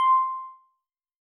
Techmino/media/sample/bell/28.ogg at 940ac3736cdbdb048b2ede669c2e18e5e6ddf77f
添加三个简单乐器采样包并加载（之后用于替换部分音效）